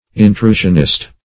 Meaning of intrusionist. intrusionist synonyms, pronunciation, spelling and more from Free Dictionary.
Intrusionist \In*tru"sion*ist\, n.